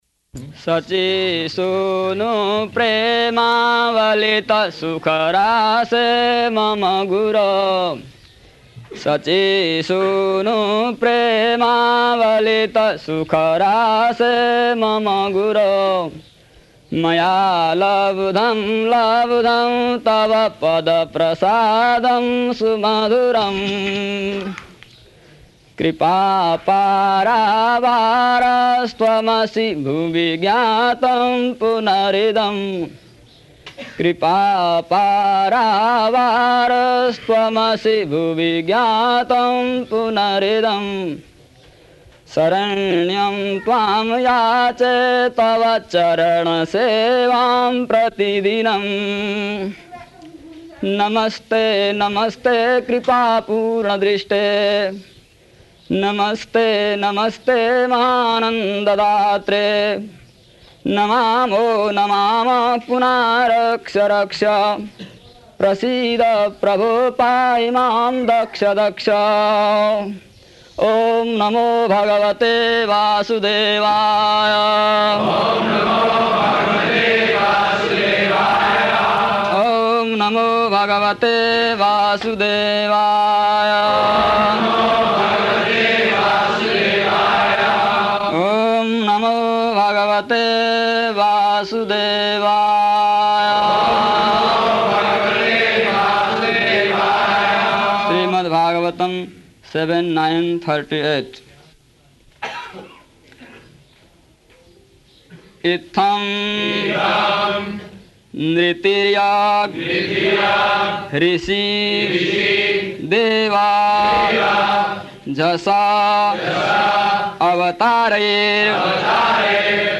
March 16th 1976 Location: Māyāpur Audio file